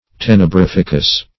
Search Result for " tenebrificous" : The Collaborative International Dictionary of English v.0.48: Tenebrificous \Ten`e*brif"ic*ous\, a. Tenebrific.
tenebrificous.mp3